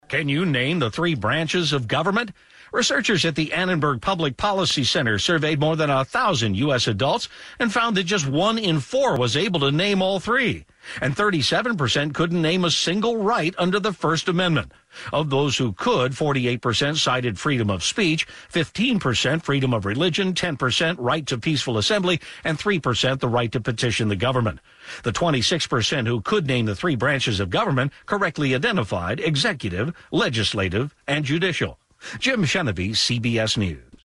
CBS Philly did a segment on the civics survey on September 26. In addition, a clip summarizing the survey’s findings was featured on a number of CBS News Radio stations.
CBS-radio-on-civics-survey.mp3